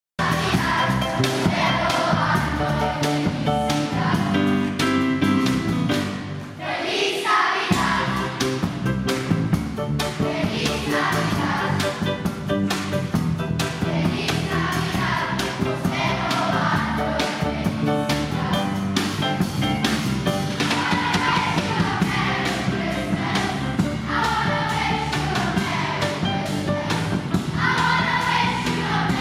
Die Schülerinnen und Schüler der Jahrgangsstufe fünf sorgen mit ihrem Gesang für ganz besondere Momente im Advent